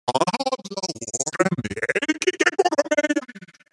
دانلود صدای ربات 4 از ساعد نیوز با لینک مستقیم و کیفیت بالا
جلوه های صوتی